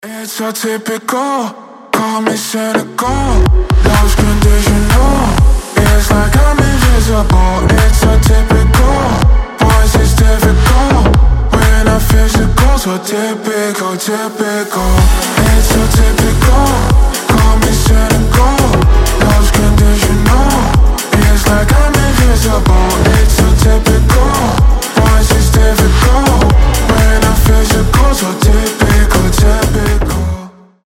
EDM
басы
slap house